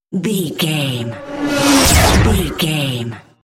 Whoosh to hit engine speed
Sound Effects
Atonal
dark
futuristic
intense
tension
the trailer effect